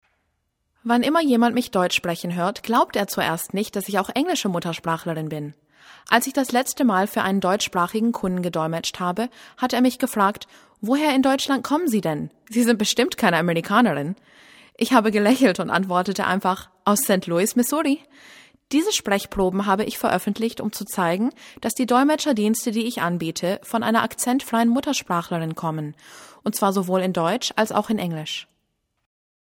The links below are voice samples for you to ascertain for yourself the accent free nature of my voice.
Story
kurze Geschichte